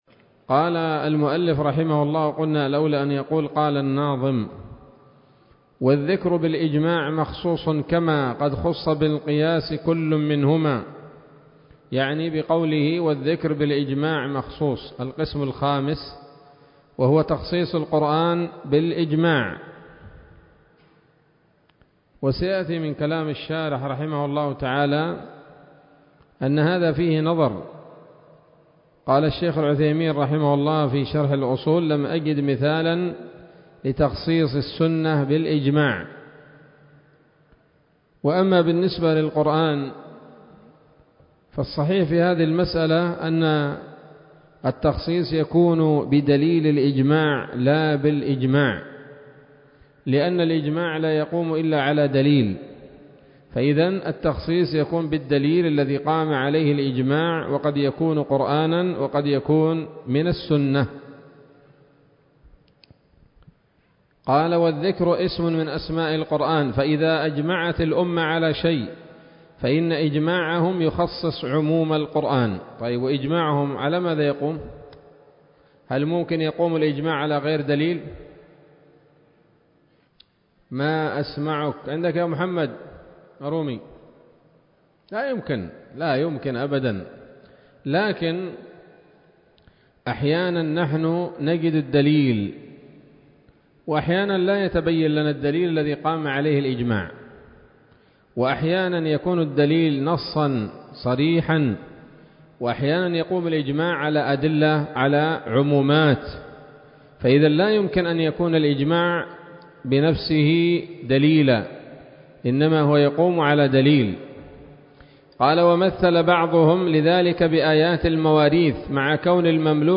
الدرس الرابع والأربعون من شرح نظم الورقات للعلامة العثيمين رحمه الله تعالى